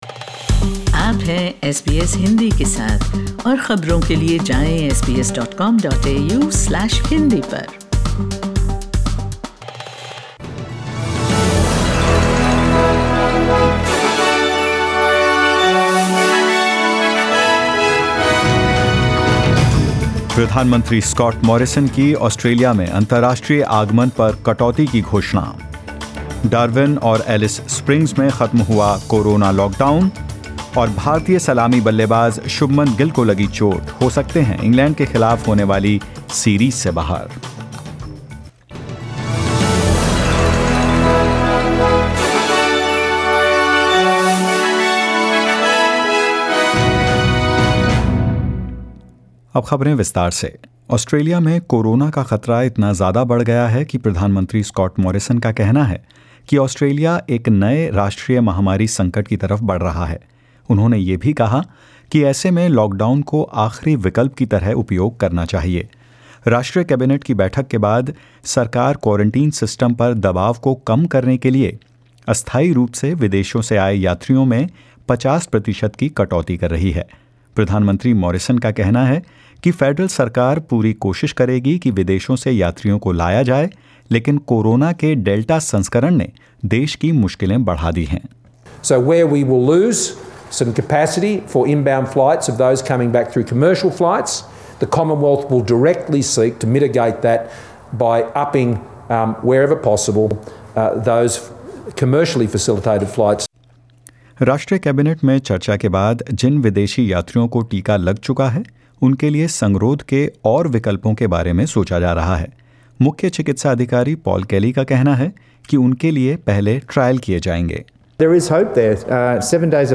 In this latest SBS Hindi News bulletin of Australia and India: The Northern Territory lifts COVID-19 lockdown orders for Darwin and Alice Springs; Injured Shubman Gill likely to miss England series and more.